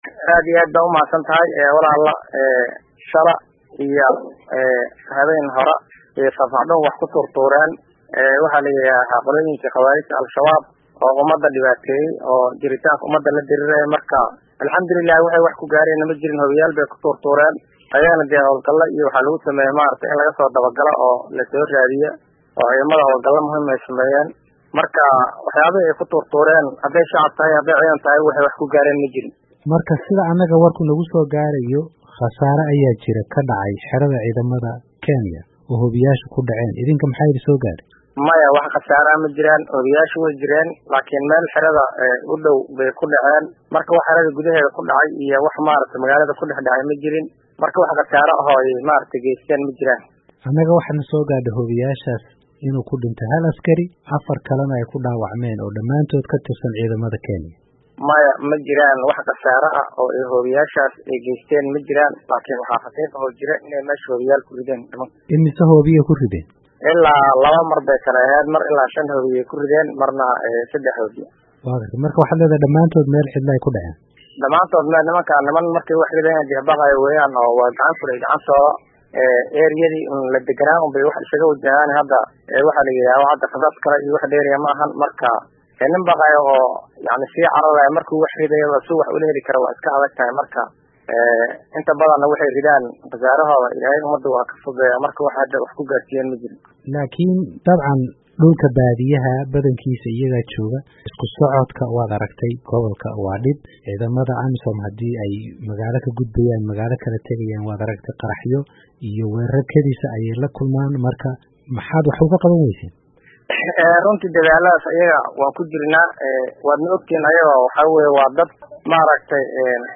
Halkan ka dhageyso wareysiga Fafaxdhuun